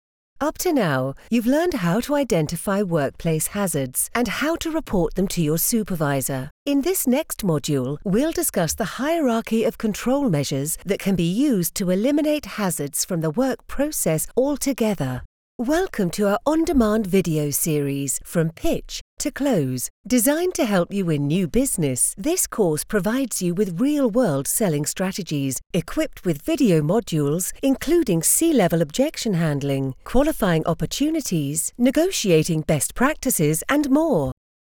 English (British)
Warm, Friendly, Versatile, Natural, Mature
E-learning